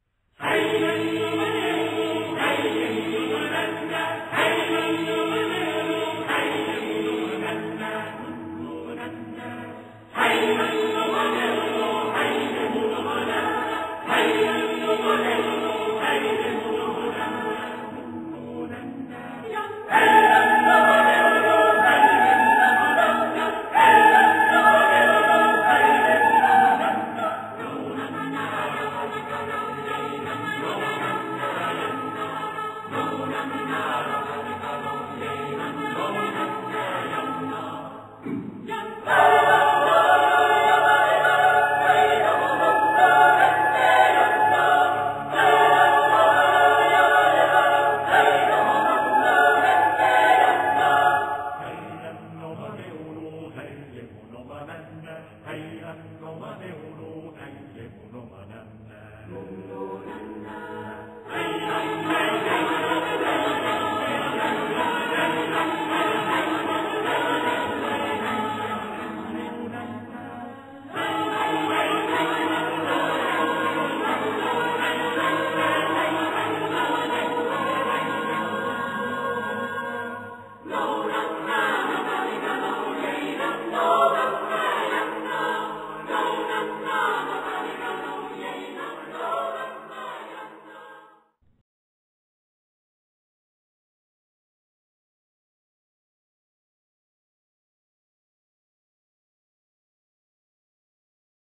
Accompaniment:      A Cappella
Music Category:      World